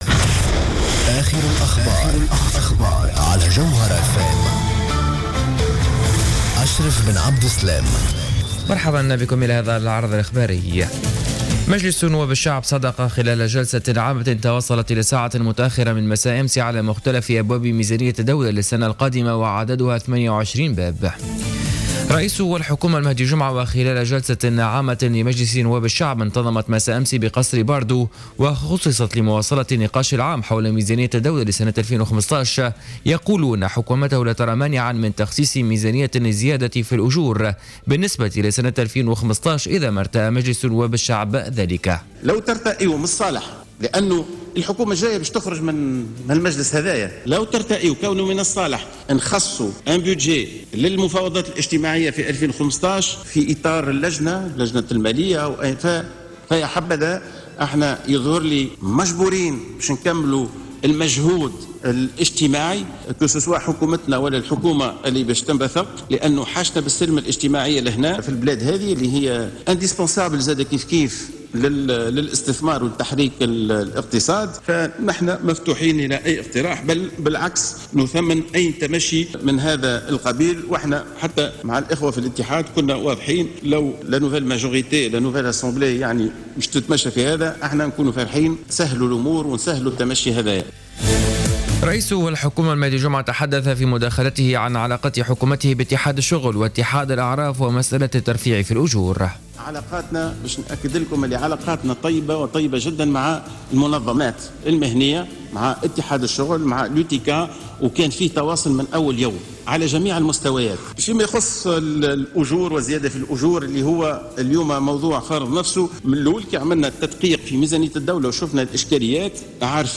نشرة أخبار منتصف الليل ليوم 11-12-14